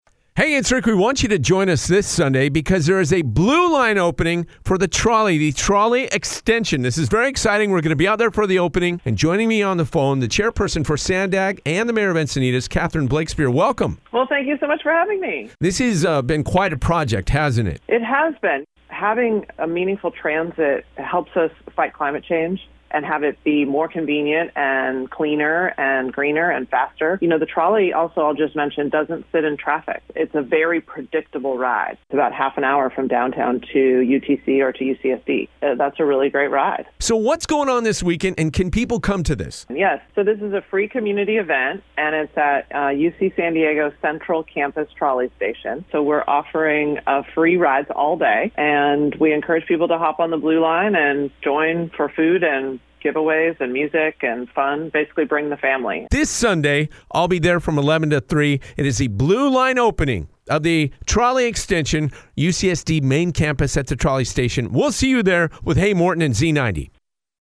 Selected Press Z90.3 FM Interview with SANDAG Chair and Encinitas Mayor Catherine Blakespear TV Advertisement – English, 30 seconds TV Advertisement – Spanish, 30 seconds TV Advertisement – English, 15 seconds TV Advertisement – Spanish, 15 seconds Media Toolkit